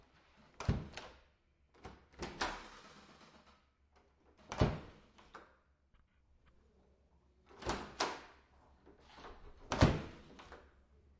随机的 " 门木制公寓内部打开关闭顺利但吱吱作响附近的房间bgsound
描述：门木公寓内部开放关闭光滑但吱吱声附近宽敞的bgsound.flac
Tag: 打开 关闭 内部 吱吱 木材 公寓